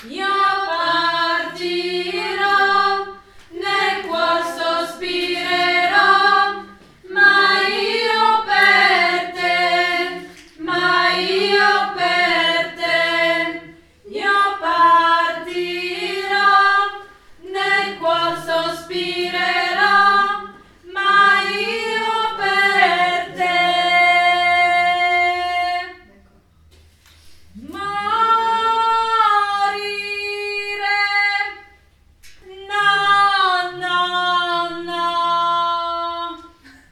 Addio_Morettin_Soprane_fin.mp3